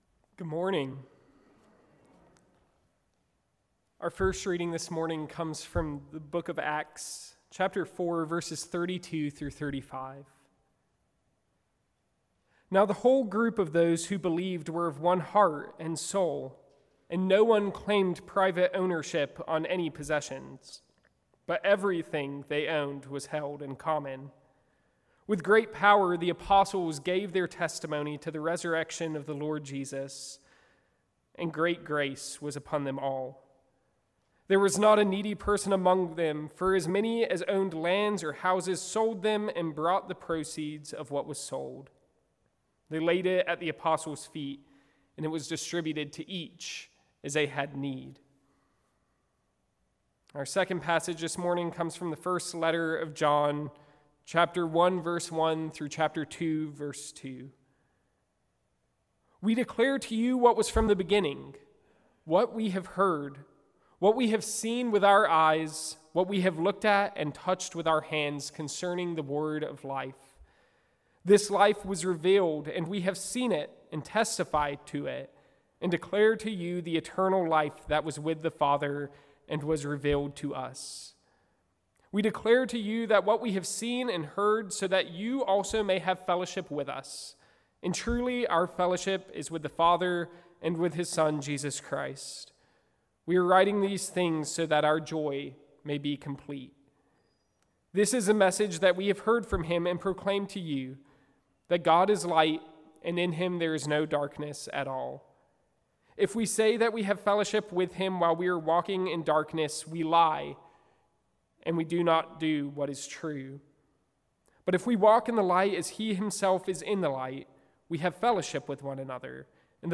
Service Type: Guest Preacher